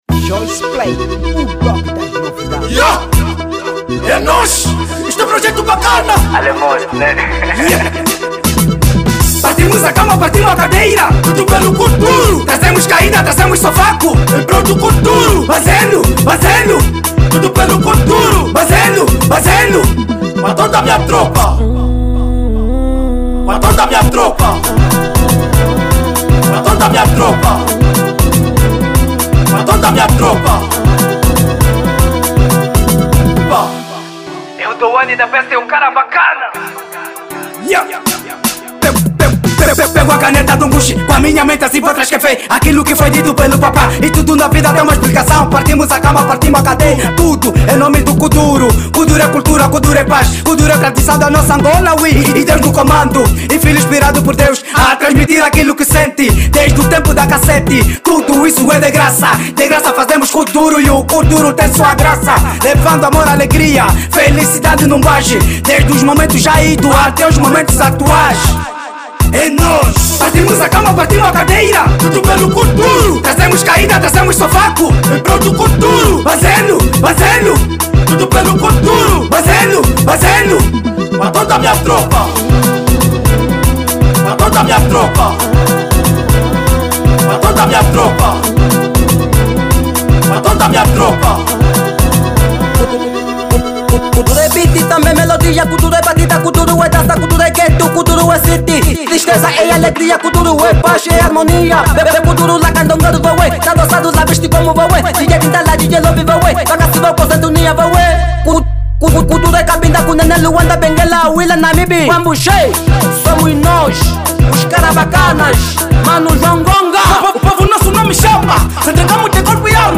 Kuduro